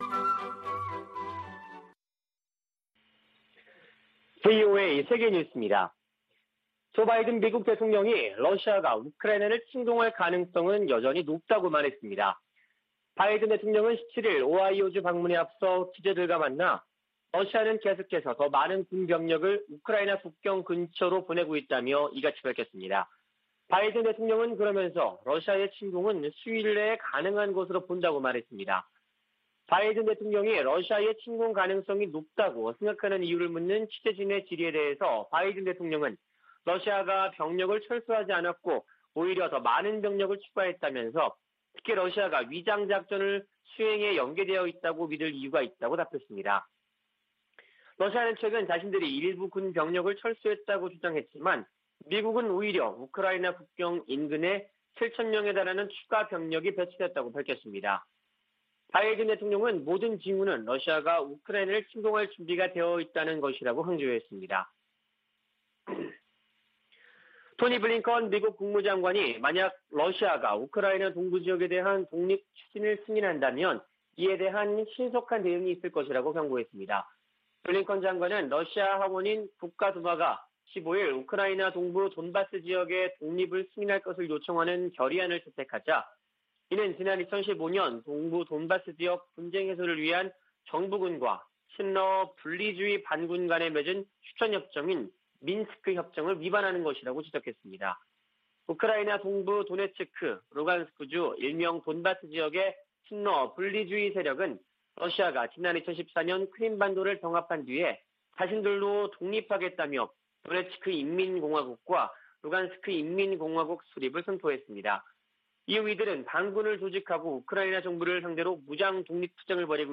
VOA 한국어 아침 뉴스 프로그램 '워싱턴 뉴스 광장' 2021년 2월 18일 방송입니다. 미국이 핵탄두 탑재 가능한 B-52H 전략폭격기 4대를 괌에 배치했습니다. 미국의 전문가들은 필요하다면 한국이 우크라이나 사태 관련 미국 주도 국제 대응에 동참해야한다는 견해를 제시하고 있습니다. 북한이 한 달 새 가장 많은 미사일 도발을 벌였지만 미국인들의 관심은 낮은 것으로 나타났습니다.